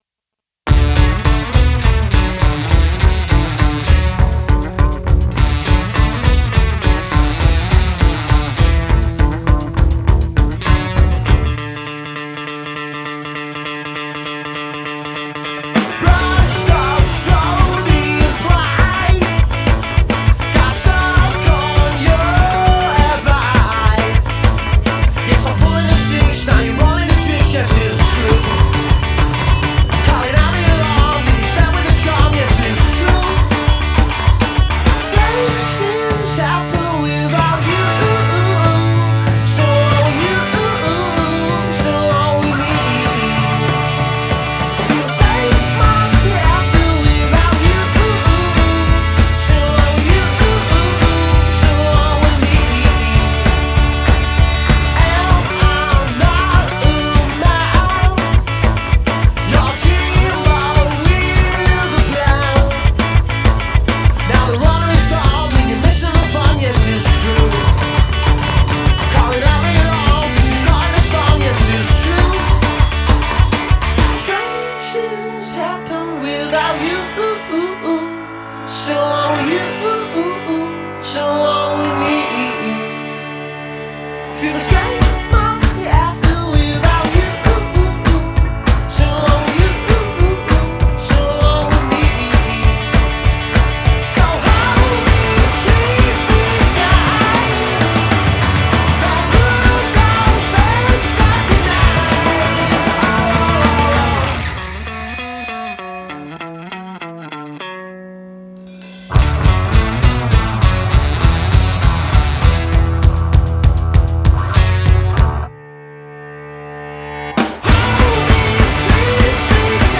Funky and funny.